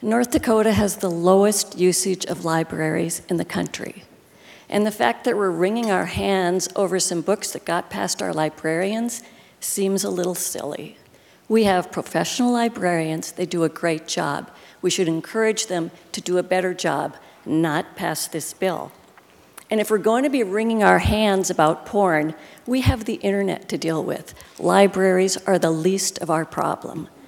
But Fargo Democratic Representative Liz Conmy argued against it.